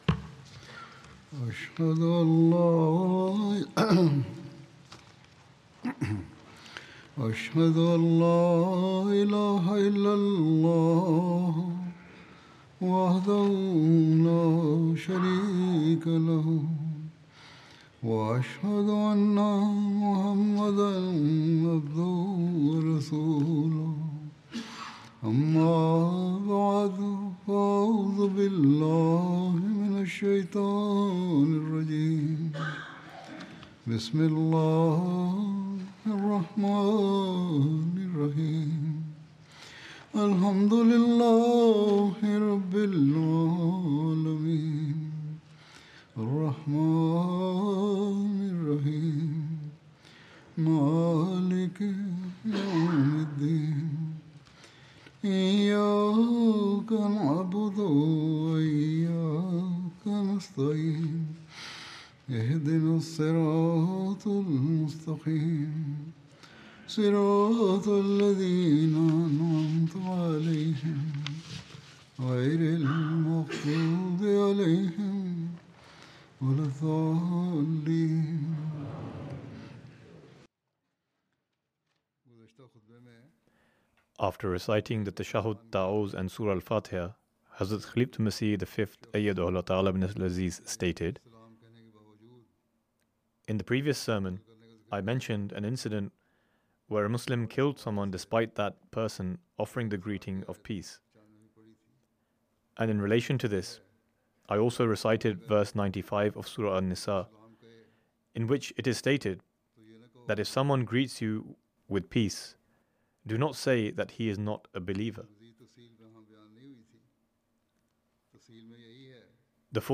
English Translation of Friday Sermon delivered by Khalifatul Masih